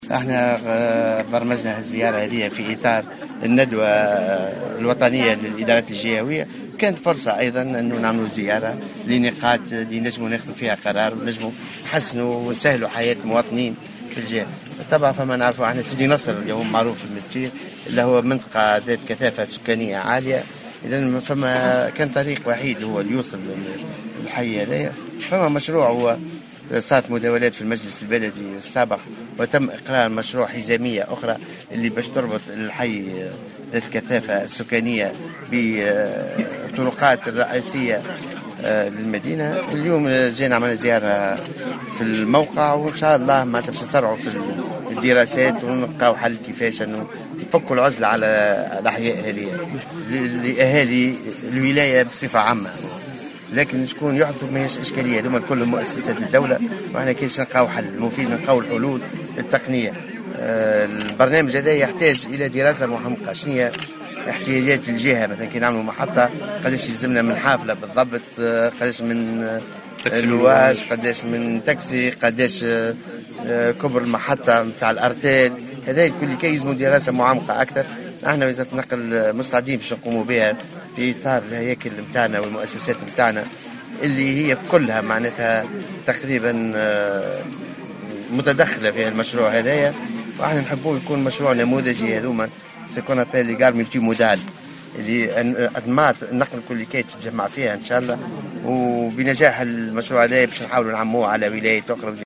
وقال غديرة في تصريح لمراسل الجوهرة أف أم، إن الوزارة تسعى لتسريع نسق الدراسات المتعلقة بعدد من المشاريع وخاصة مشروع الطريق الحزامية الذي يربط المنستير بحي سيدي نصر.